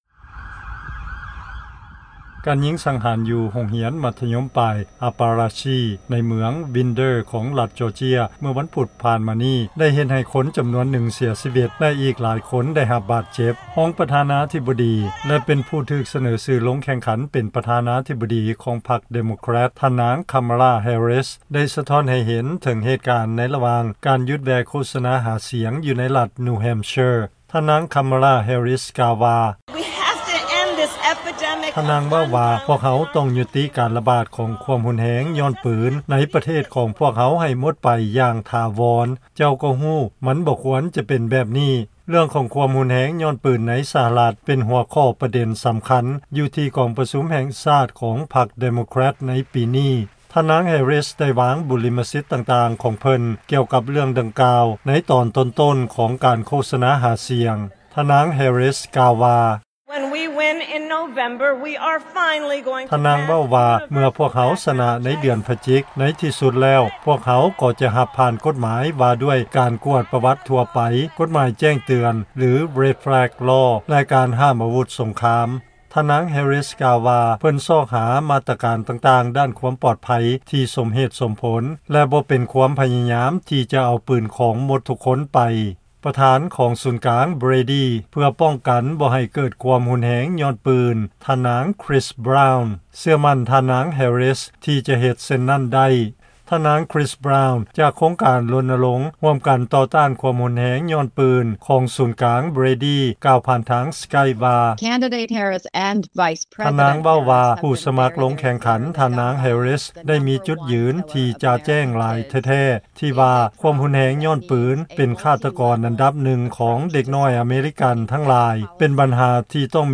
Vice President and Democratic Presidential Nominee Kamala Harris reflected on the incident during a campaign stop in New Hampshire.
(Kamala Harris, Democratic Presidential Nominee)
(Donald Trump, Republican Presidential Nominee)